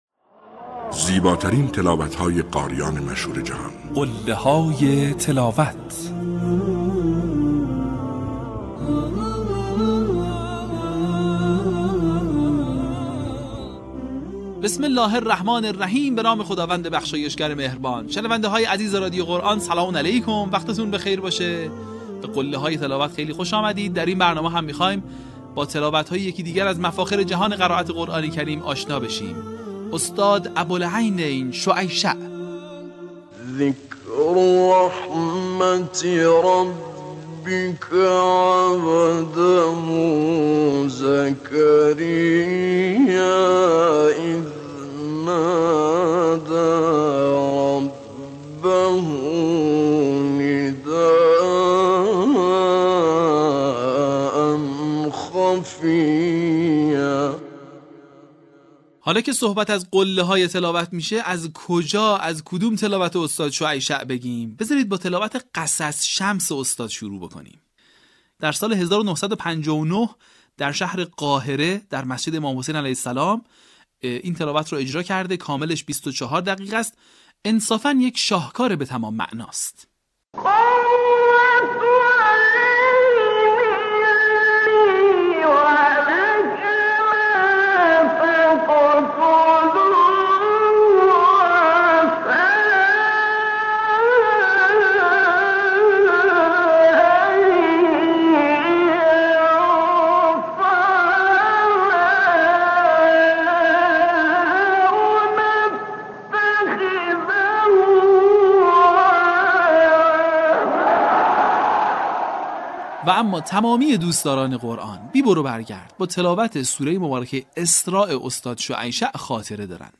این مجموعه شامل تلاوت‌های ماندگار قاریان بین‌المللی مصری است که تاکنون 40 قسمت آن از رسانه ایکنا منتشر شده است.
قله‌های تلاوت - ابوالعینین شعیشع